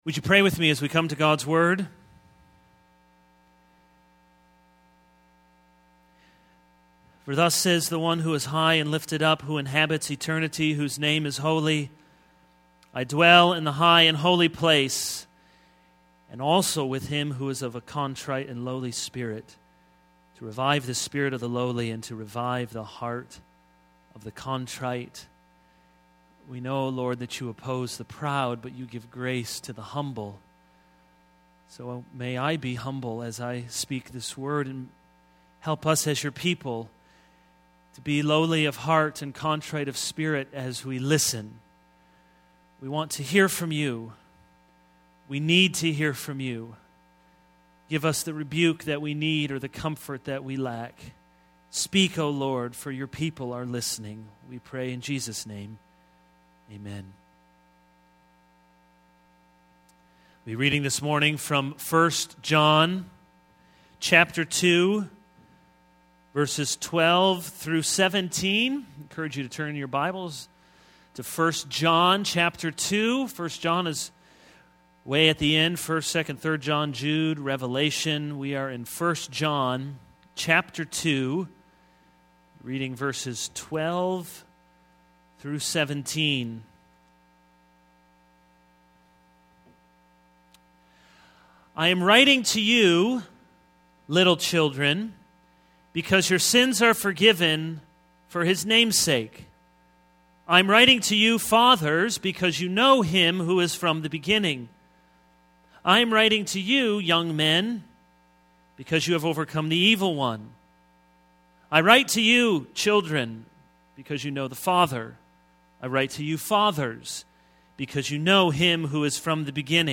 This is a sermon on 1 John 2:12-17.